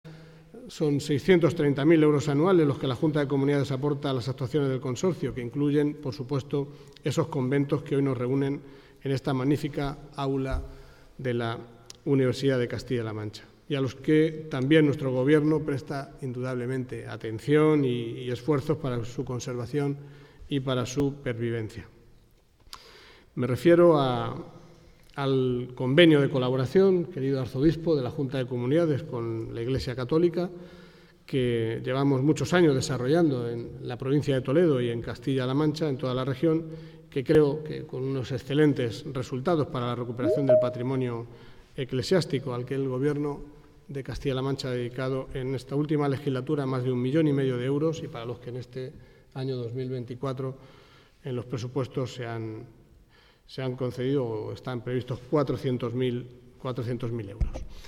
Álvaro Gutiérrez ha participado en la inauguración del I Congreso Internacional de Conventos que se celebra en Toledo